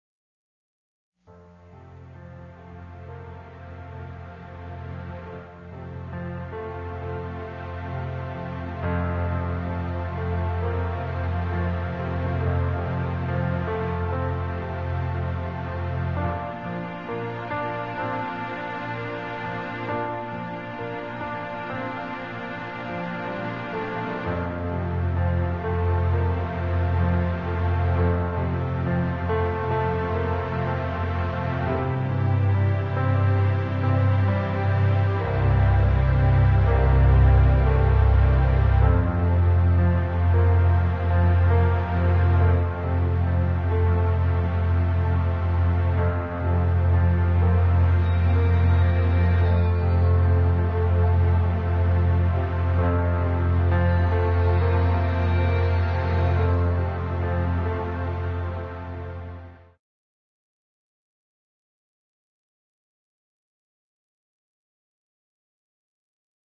Musik für Meditation
Entspannungsmusik
Meditationsmusik